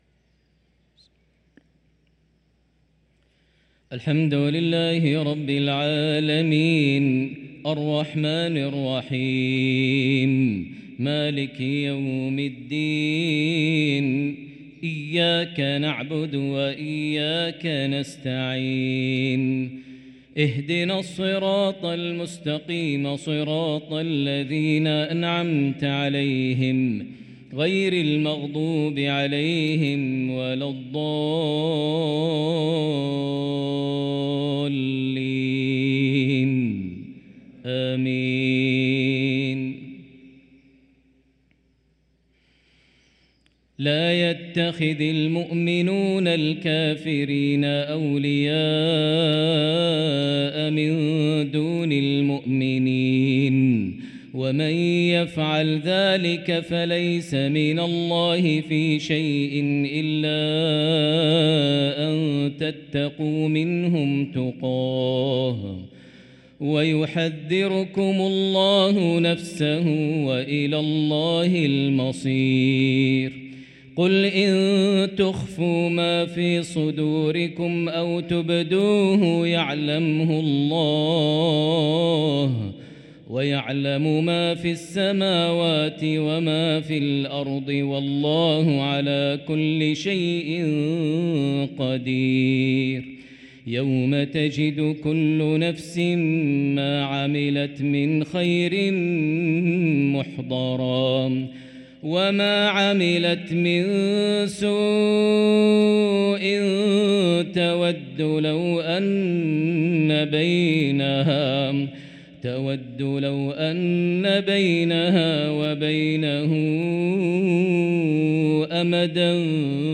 صلاة المغرب للقارئ ماهر المعيقلي 16 ربيع الأول 1445 هـ
تِلَاوَات الْحَرَمَيْن .